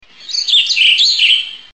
Warbling Vireo (Vireo gilvus)
querulous twee (P,L).
A drawling nasal shree, shree (L).